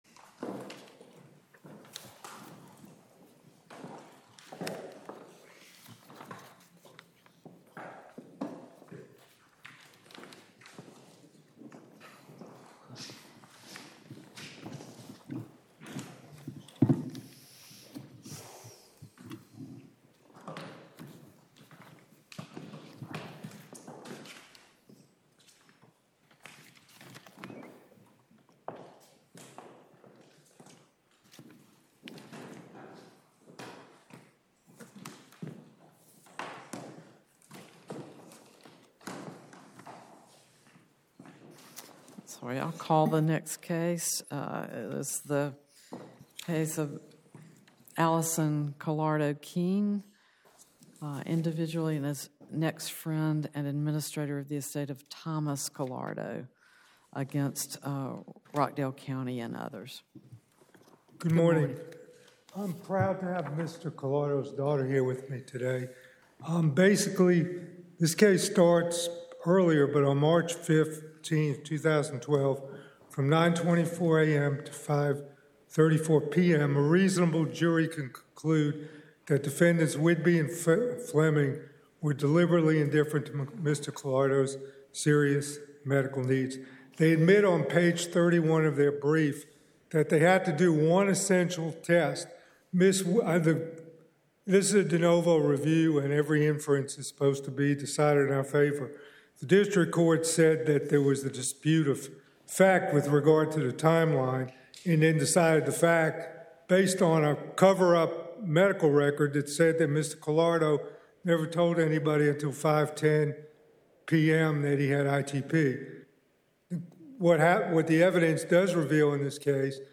Oral Argument Recordings | Eleventh Circuit | United States Court of Appeals